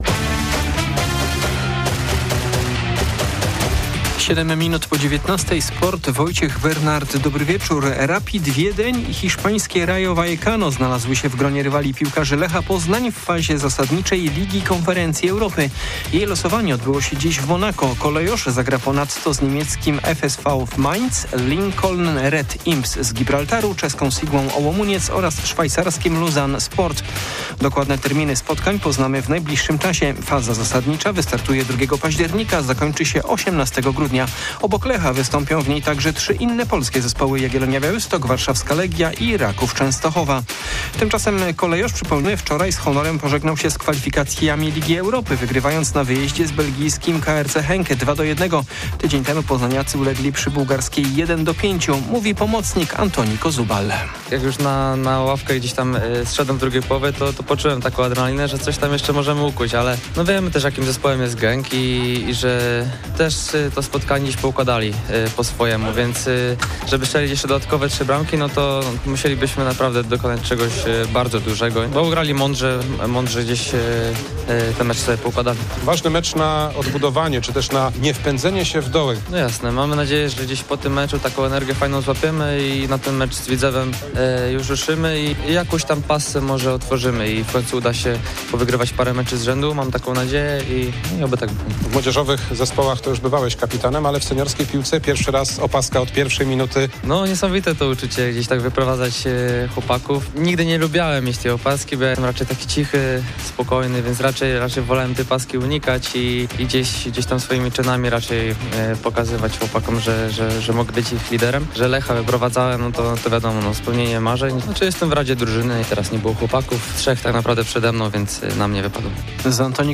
29.08.2025 SERWIS SPORTOWY GODZ. 19:05